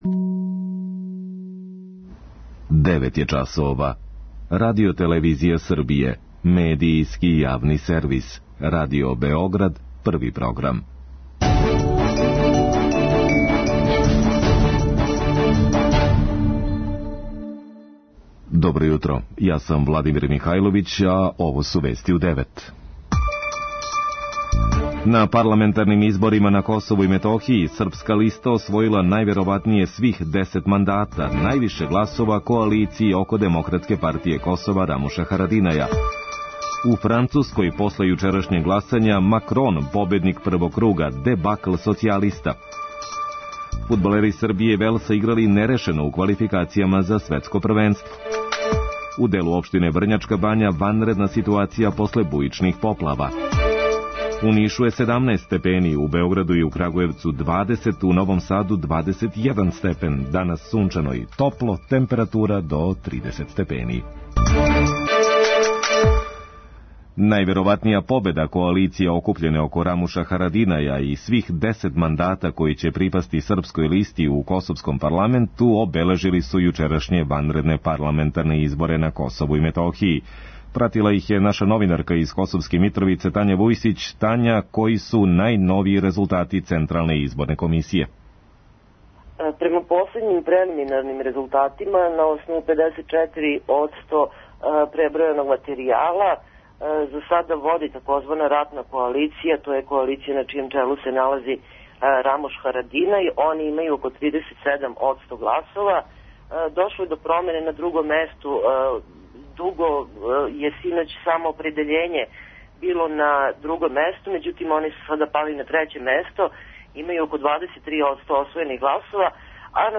преузми : 3.87 MB Вести у 9 Autor: разни аутори Преглед најважнијиx информација из земље из света.